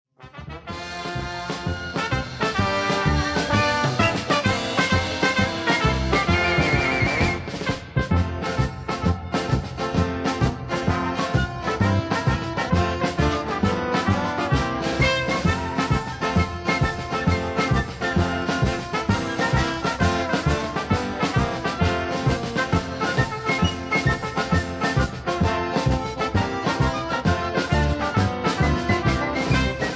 Key-F
Tags: backing tracks , irish songs , karaoke , sound tracks